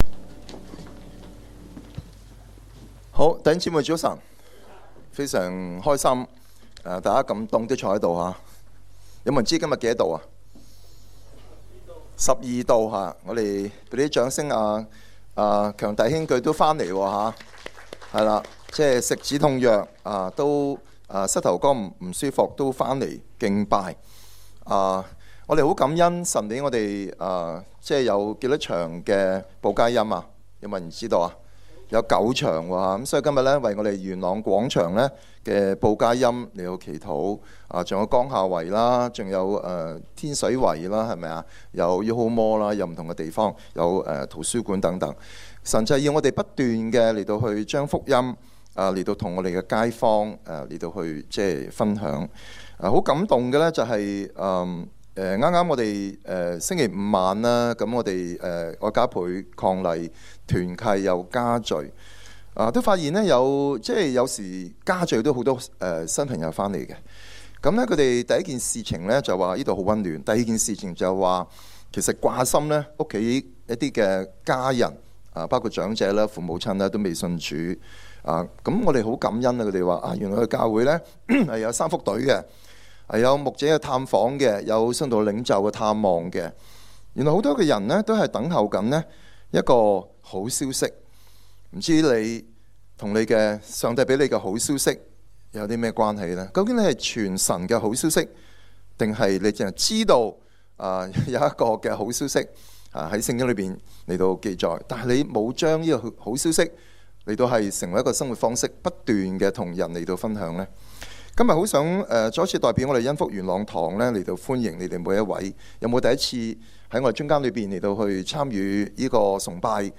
证道集